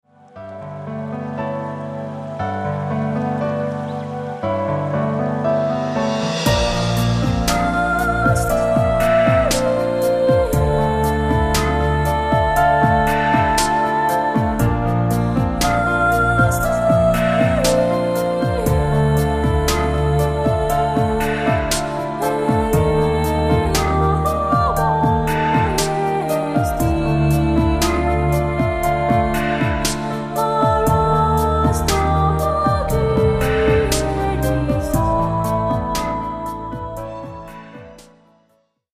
インストを中心にコーラスも織り交ぜた計5曲、20分程度のオリジナル曲を収録。
「聞き込む音楽」というよりは、さりげなく部屋で聞く音楽を目指しました。
ジャンル： EasyListening, NewAge